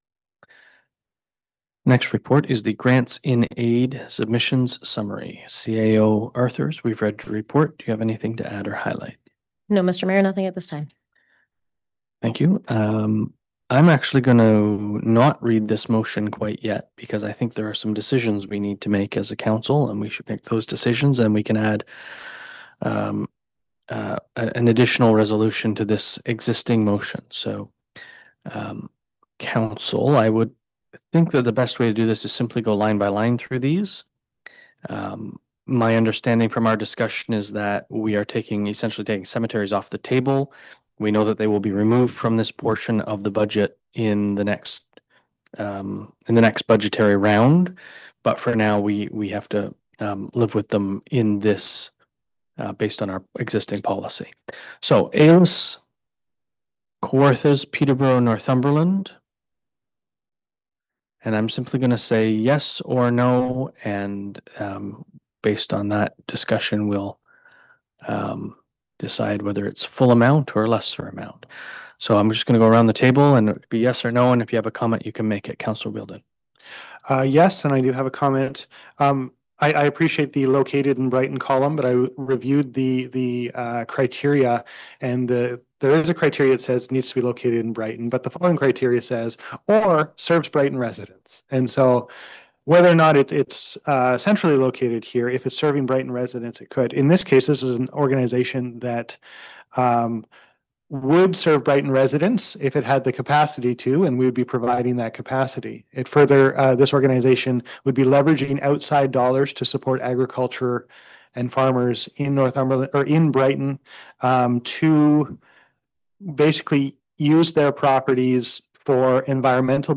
Mayor Brian Ostrander and council members discussed the eligibility of organizations, particularly those serving Brighton residents, and the potential economic and environmental benefits.
Brighton council reviewed the community grants program at a recent meeting.